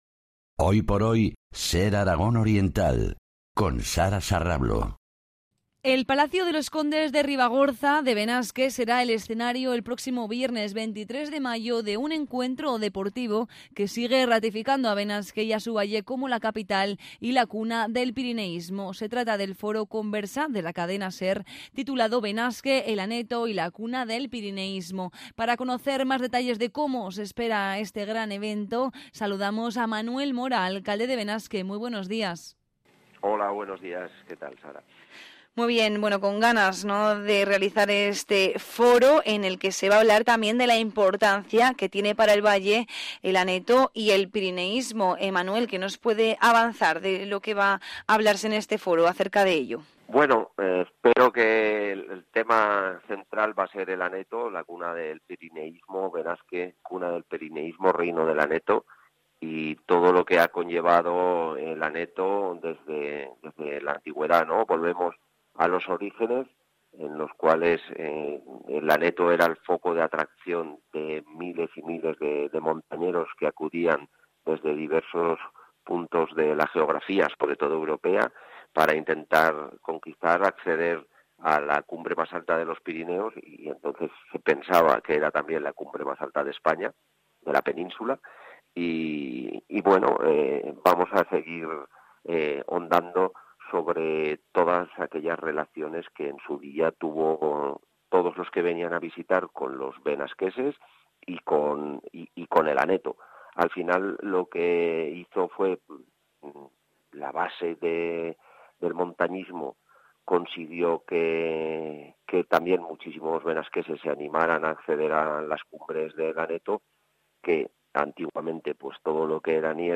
Entrevista a Manuel Mora, alcalde de Benasque.